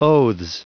Prononciation du mot oaths en anglais (fichier audio)
Prononciation du mot : oaths